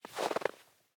Minecraft Version Minecraft Version latest Latest Release | Latest Snapshot latest / assets / minecraft / sounds / block / powder_snow / step10.ogg Compare With Compare With Latest Release | Latest Snapshot